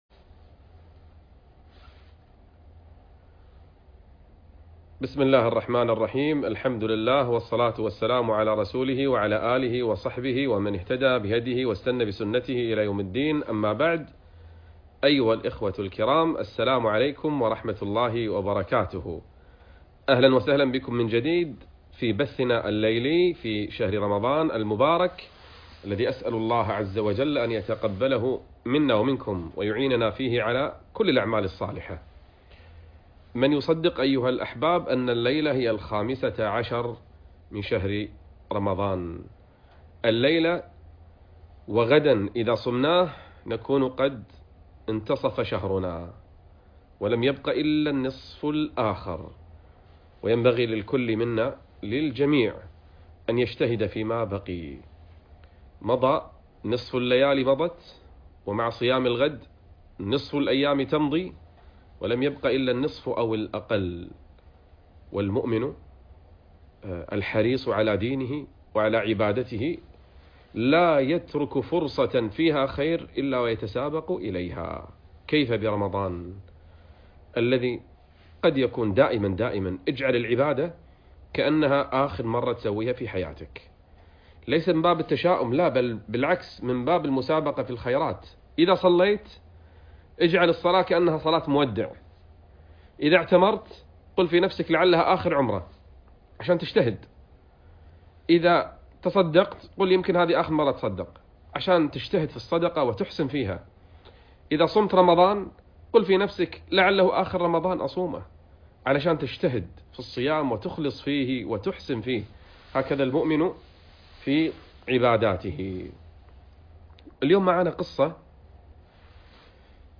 بث مباشر مع قصص من أجمل قصص التاريخ - فضيلة الشيخ نبيل العوضي